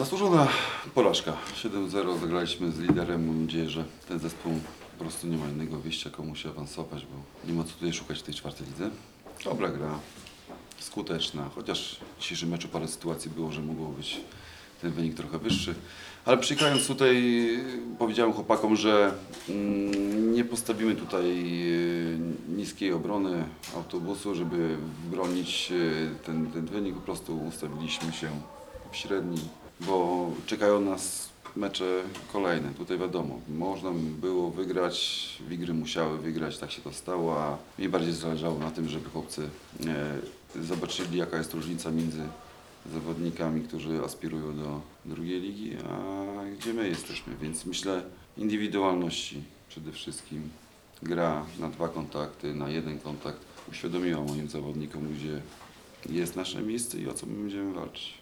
konferencja prasowa